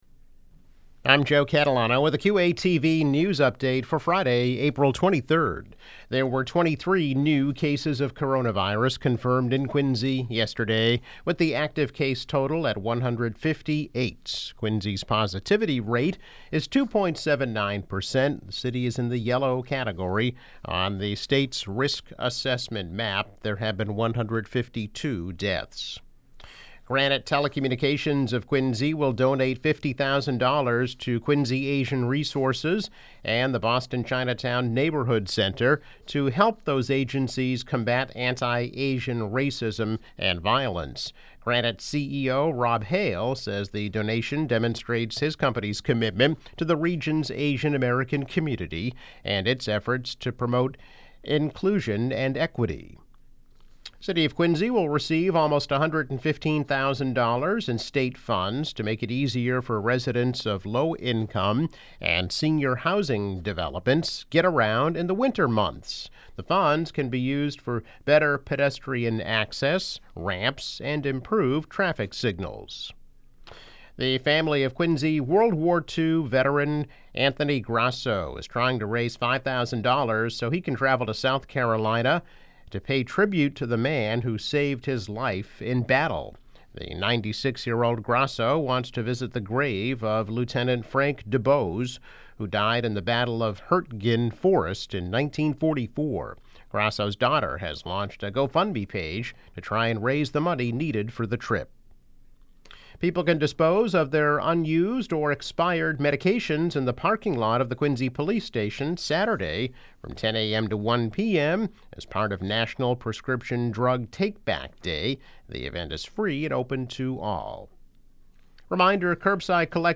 News Update - April 23, 2021